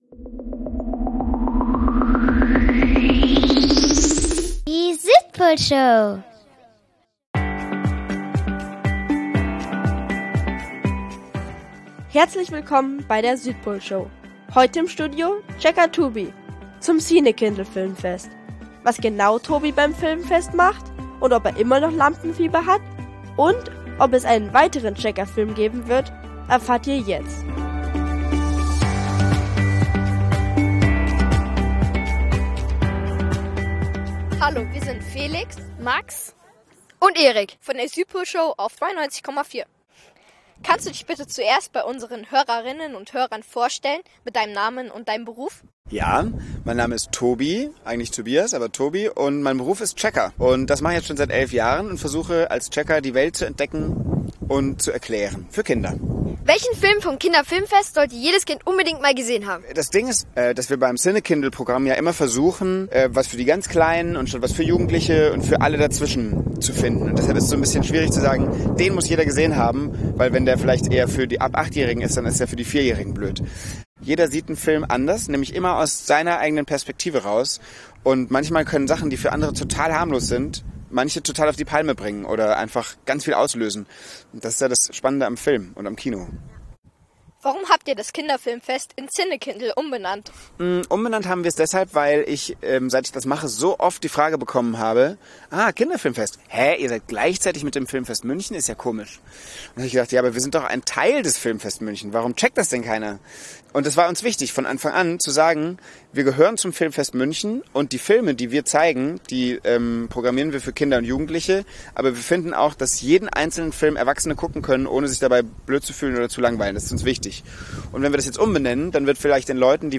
Wir durften ihn auf dem Fest interviewen. Mitten im Interview hat uns dann der Regen überrascht, aber nach einer Rettung nach drinnen konnte das Interview wie geplant weitergehen.